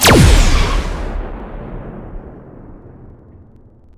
pulseCannon.wav